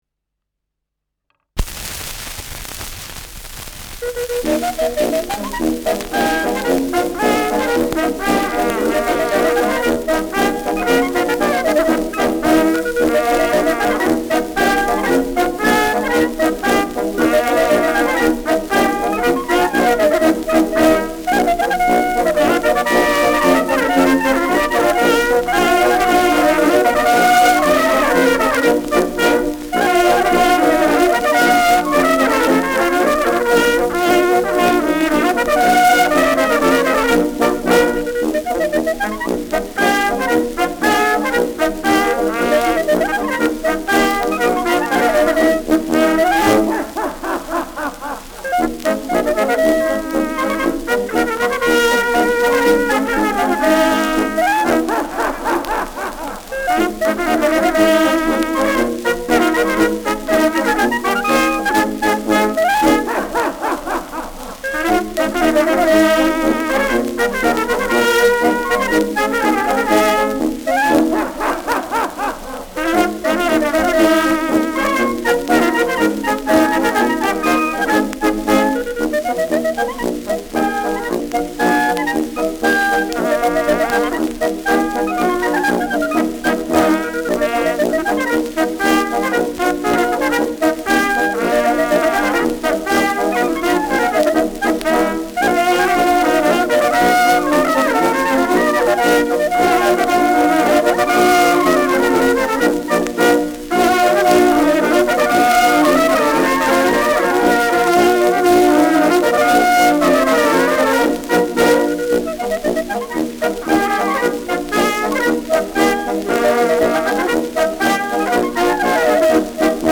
Schellackplatte
präsentes Rauschen : leichtes Knistern
Mit Lach-Einwürfen.